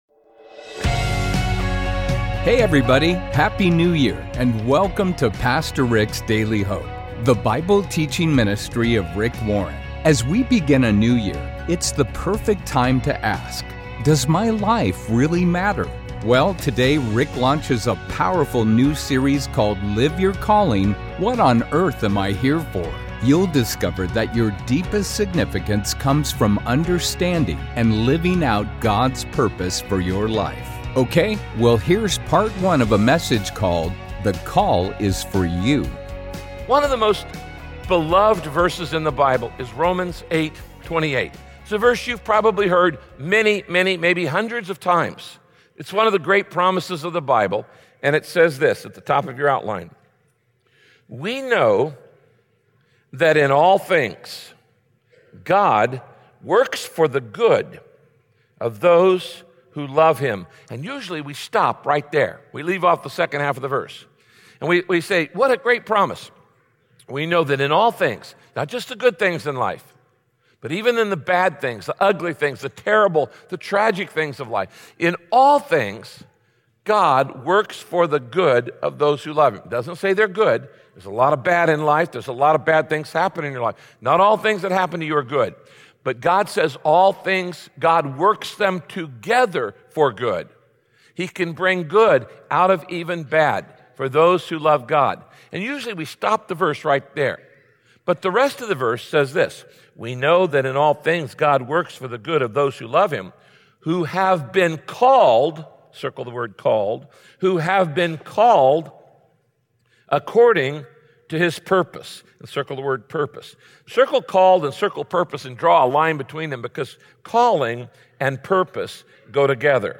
Join Pastor Rick as he teaches what the Bible says about every Christian's call to service.